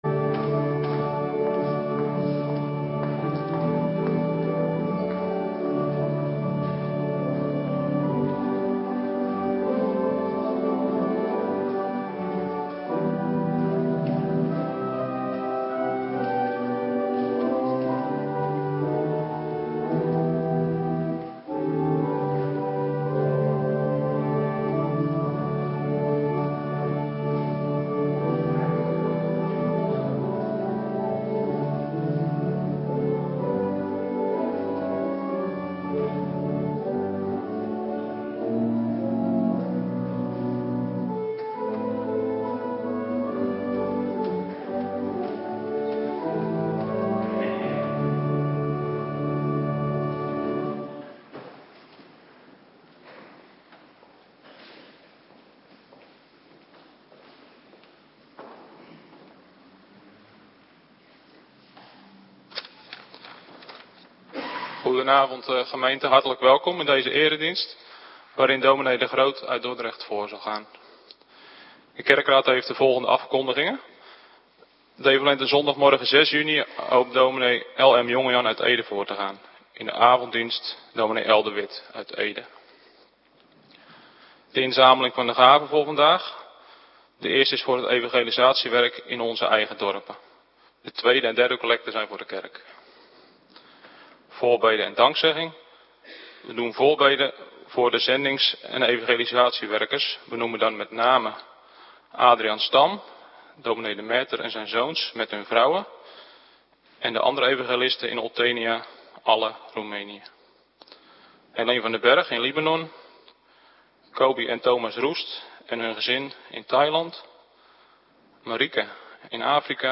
Avonddienst - Cluster 1
Locatie: Hervormde Gemeente Waarder